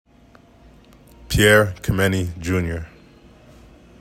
Pronunication:  p YAIR  keh MEN KNEE